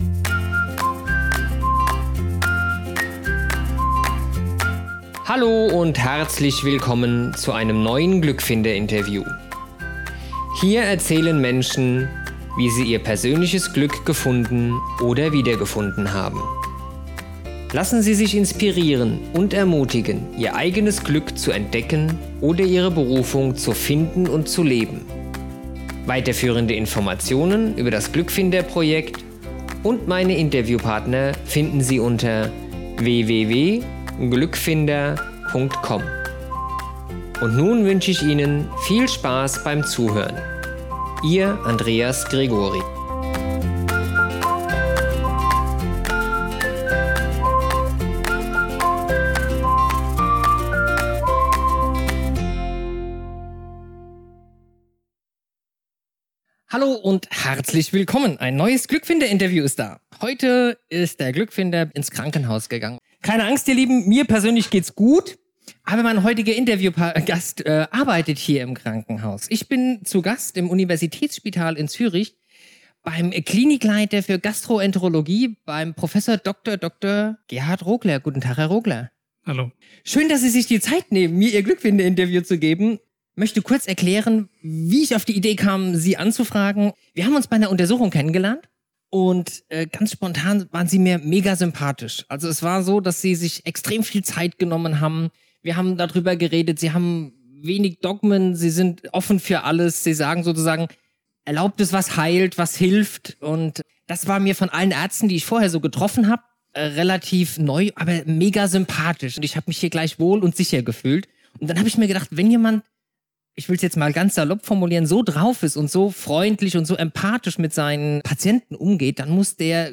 Innere Ruhe macht glücklich - Interview